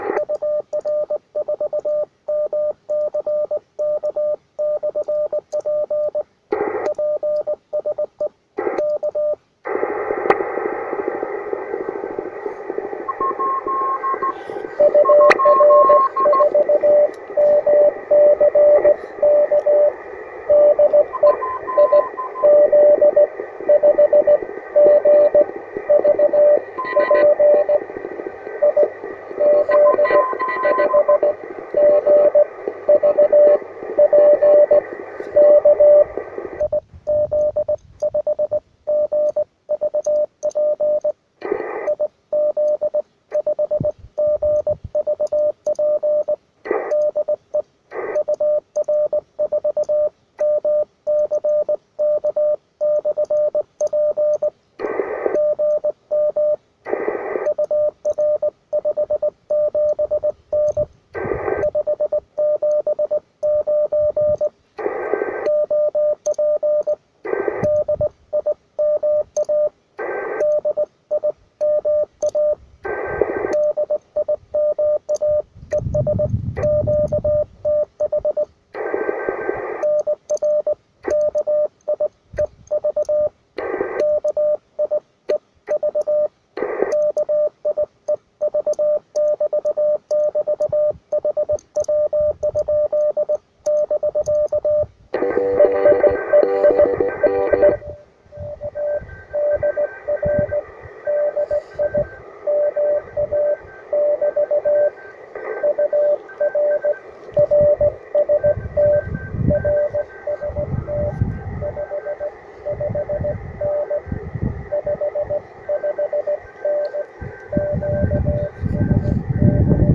Причем это не локальный, а именно эфирный широкополосный шум с характерным призвуком.
Сигналы станций шли с большими, но ооочень медленными QSB.
Порой на 14060 стоял "пчелиный рой" и ничего разобрать нельзя было.
Мое QSO с итальянцем